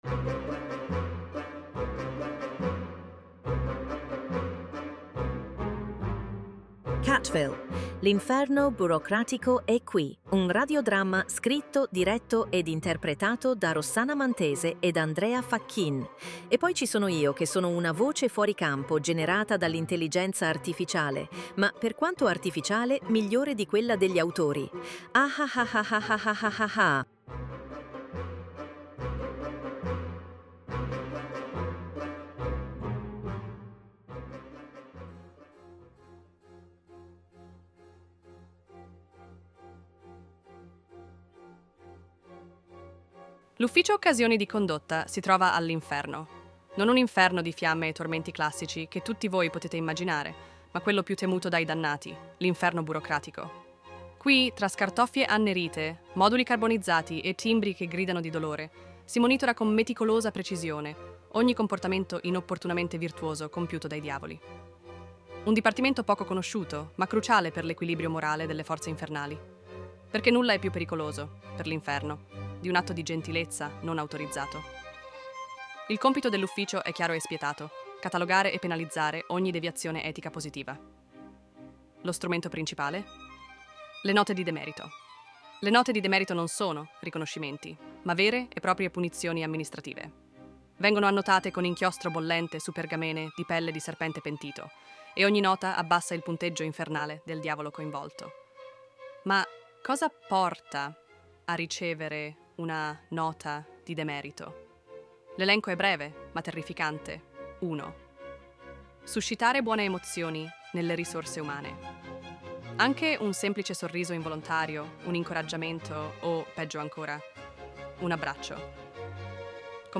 Un radio-dramma dark comedy scritto